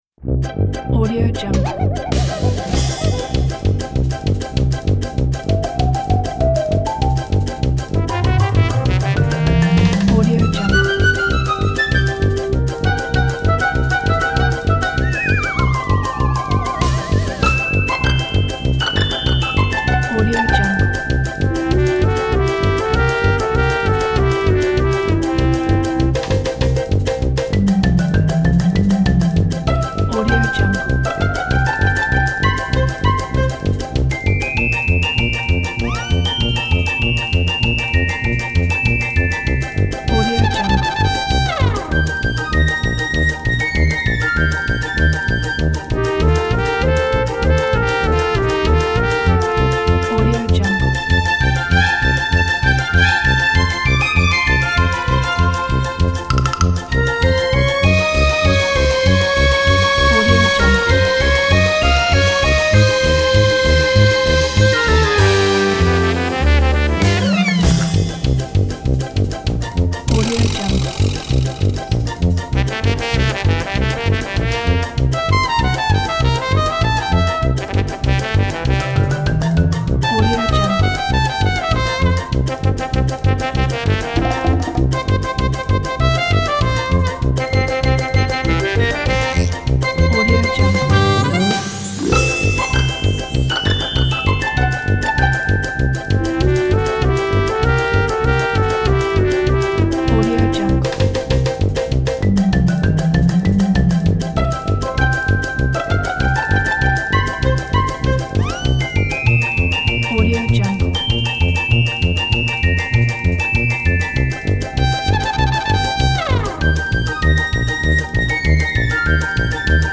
کودک